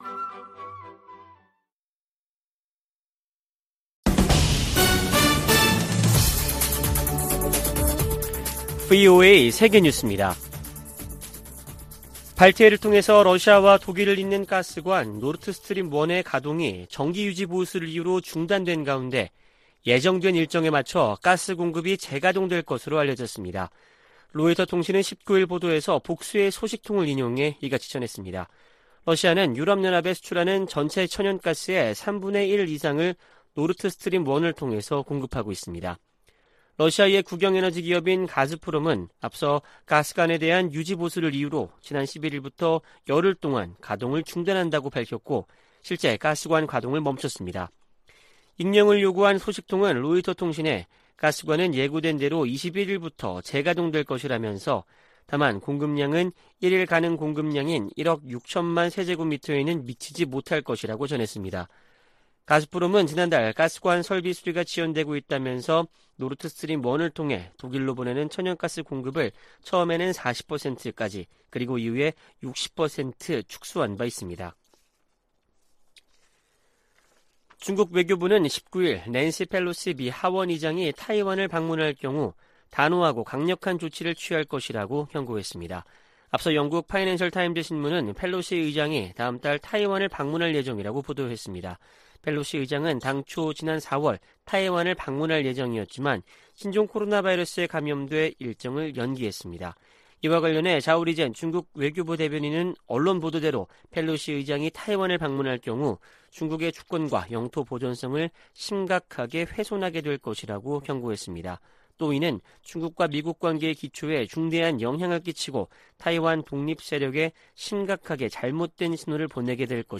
VOA 한국어 아침 뉴스 프로그램 '워싱턴 뉴스 광장' 2022년 7월 20일 방송입니다. 주한미군은 미한 공동 안보 이익을 방어하기 위해 필요하다는 입장을 미 국방부가 확인했습니다. 한국을 방문한 미 재무장관은 탄력성 있는 공급망 구축을 위한 협력을 강조하며 중국의 시장 지배적 지위를 막아야 한다고 말했습니다. 미 국무부가 북한의 인권 상황은 대량살상무기 만큼이나 우려스러운 부분이라고 지적했습니다.